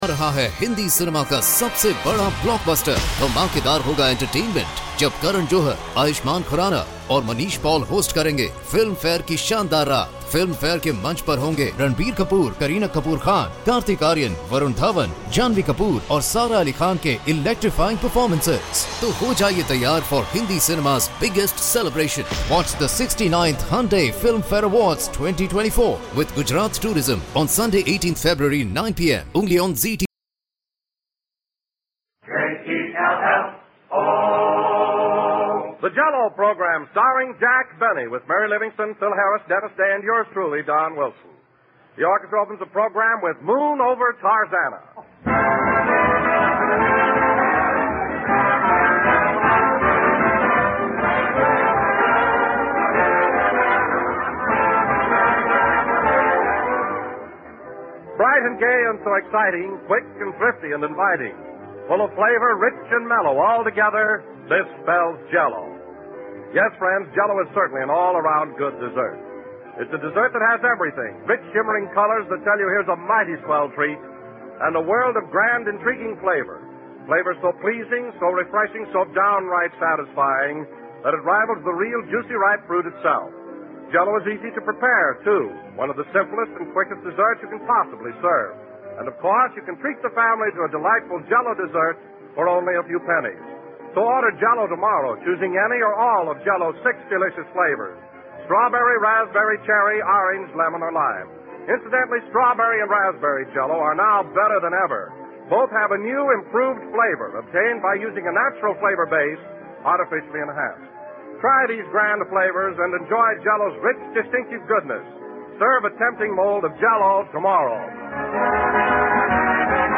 OTR Radio Christmas Shows Comedy - Drama - Variety.